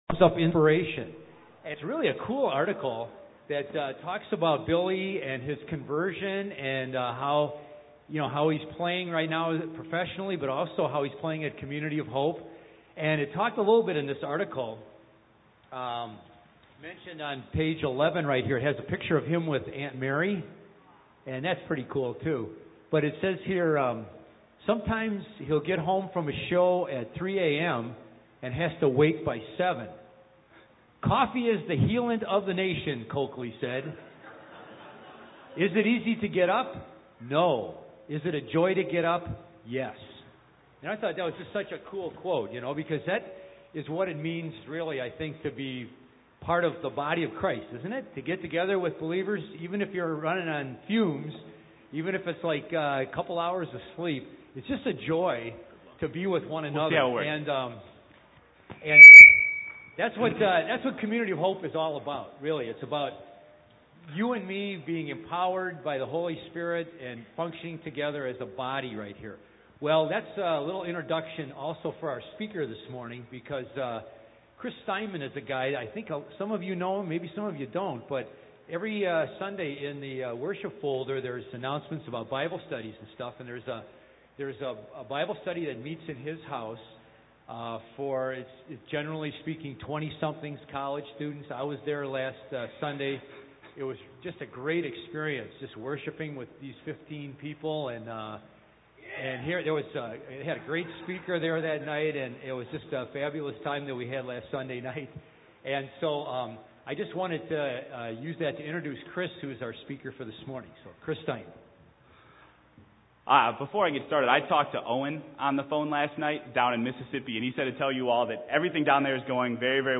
Find a previous sermon | Subscribe to COH's Sermon Podcast